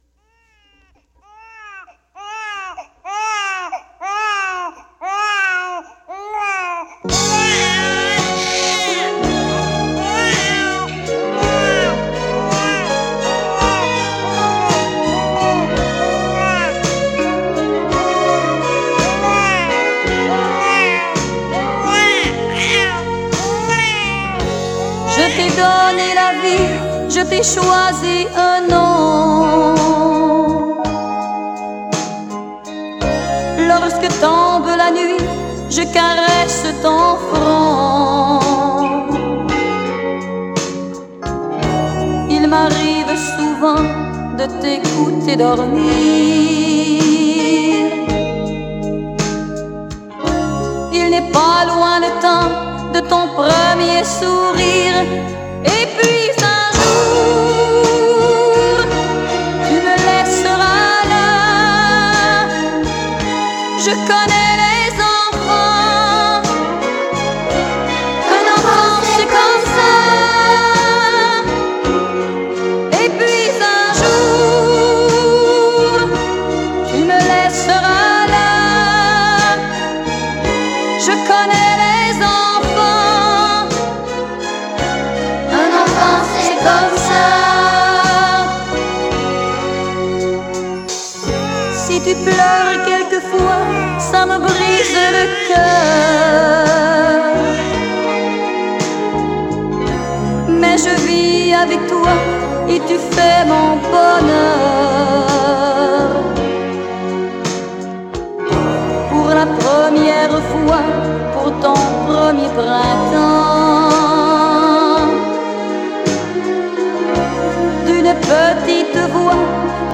Son: stéréo
Enregistrement: Studio St-Charles à Longueuil